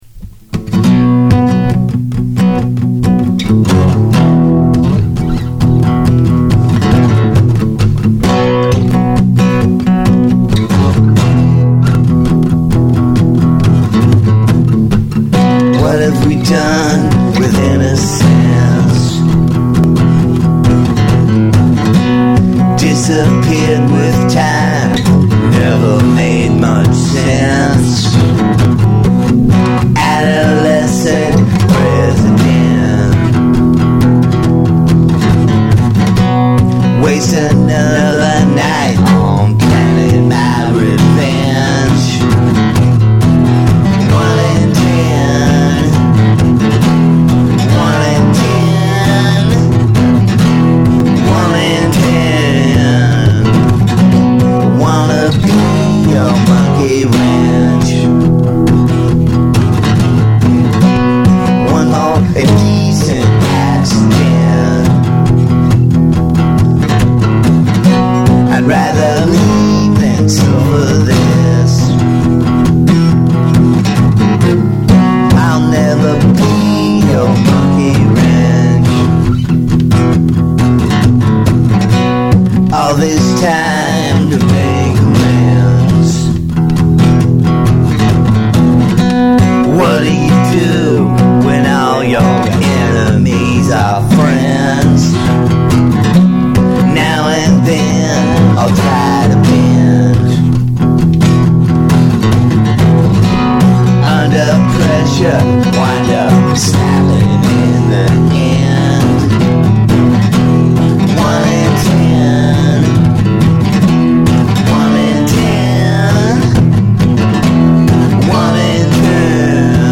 grunge cover